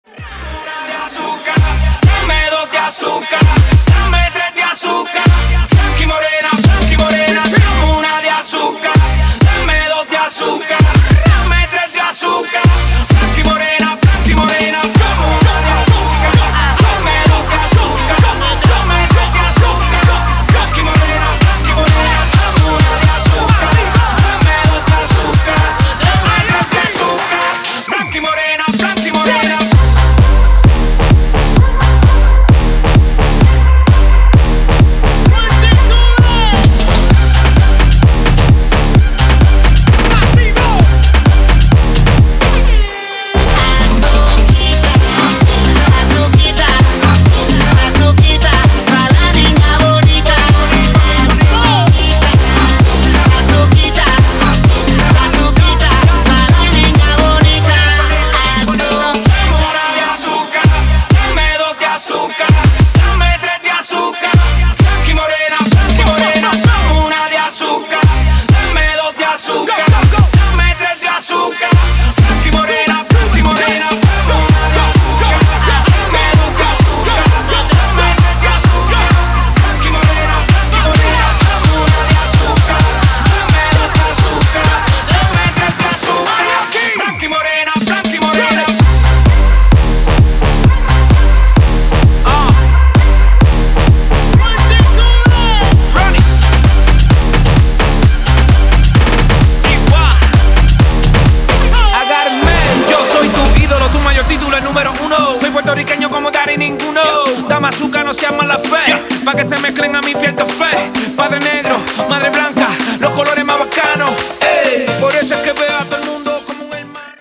• Качество: 320, Stereo
мужской вокал
громкие
заводные
dance
Reggaeton
Latin Pop